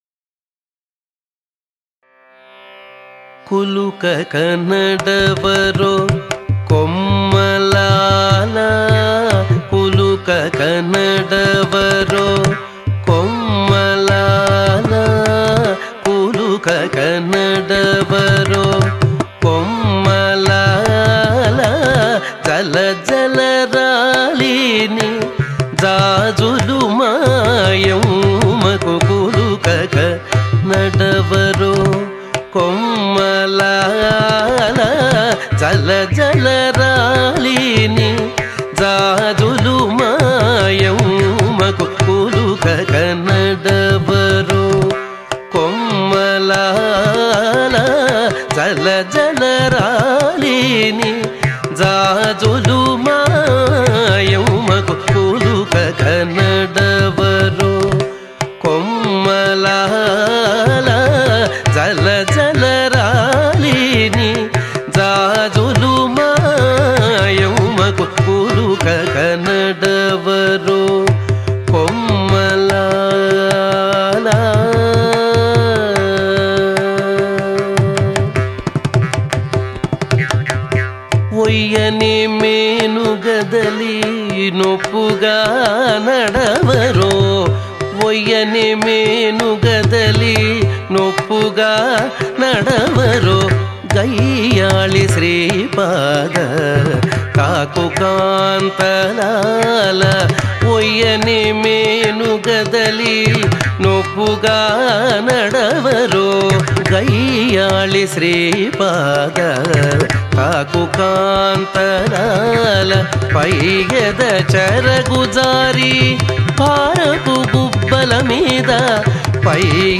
సంకీర్తన
కులుకక నడవరో కొమ్మలాలా (రాగం - దేసాళం)
పాడినవారు సంగీతం గరిమెళ్ళ బాలకృష్ణప్రసాద్